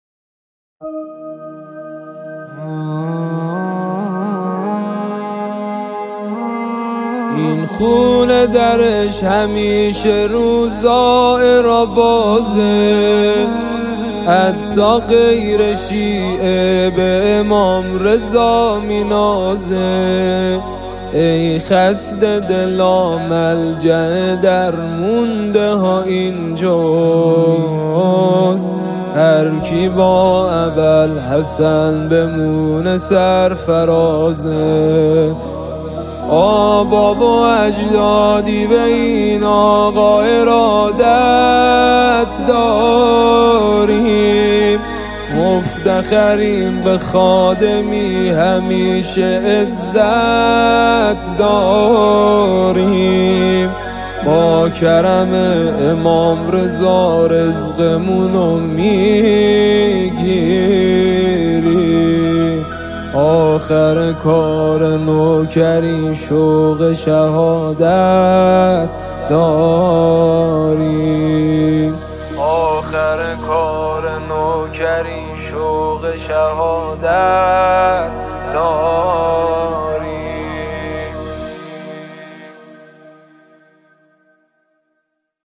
مداحی احساسی استودیویی